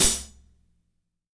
-16  HAT 3-R.wav